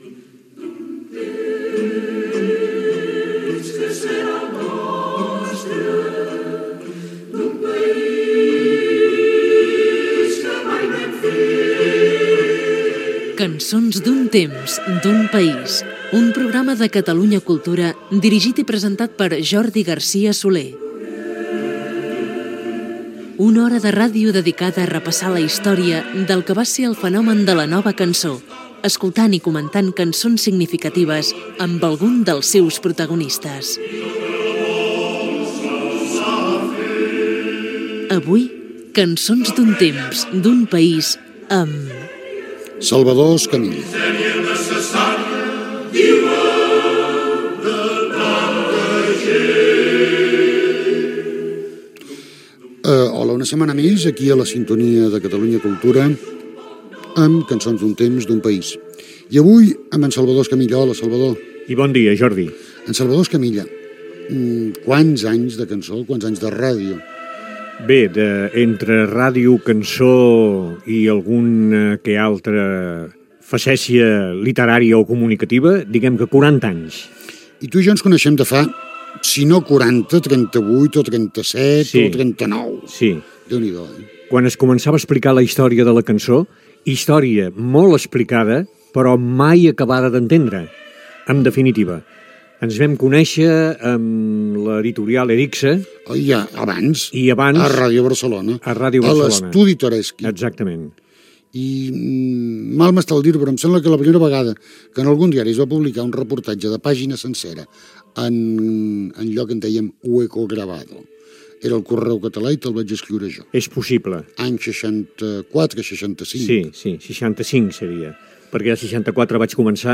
Careta del programa, espai dedicat a conversar amb el cantant i locutor Salvador Escamilla Gènere radiofònic Musical